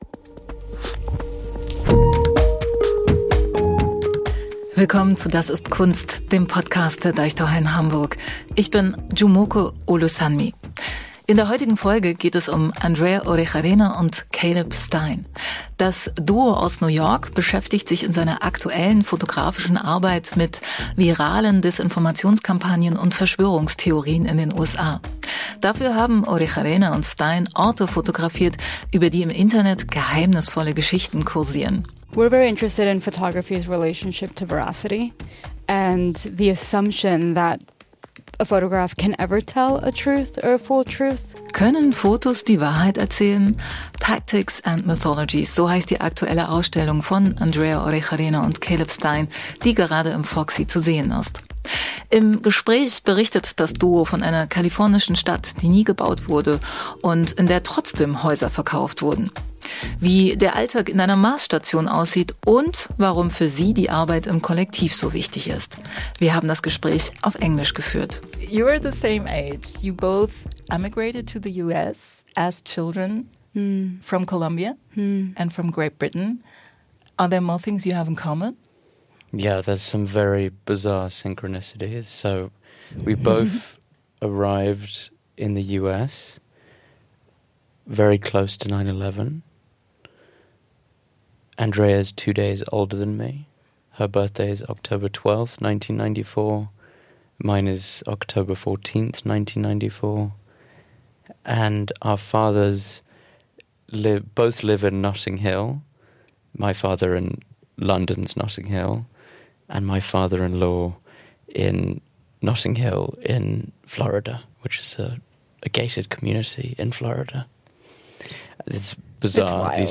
Im Gespräch mit Künstler*innen, Kurator*innen und Expert*innen wirft DAS IST KUNST einen genaueren Blick auf die Ausstellungen in den Deichtorhallen Hamburg, stellt Zusammenhänge her und knüpft an aktuelle Themen aus Kunst und Gesellschaft an.